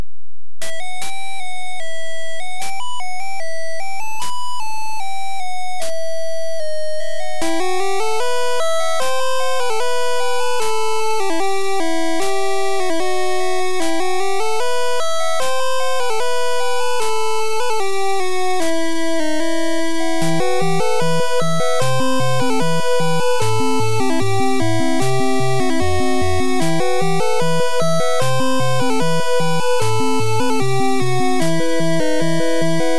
the level 1 theme.